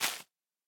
Minecraft Version Minecraft Version 1.21.5 Latest Release | Latest Snapshot 1.21.5 / assets / minecraft / sounds / block / sponge / break3.ogg Compare With Compare With Latest Release | Latest Snapshot
break3.ogg